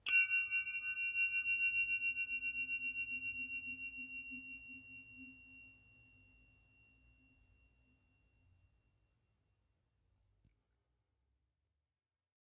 Triangle Sml Strike Spins 3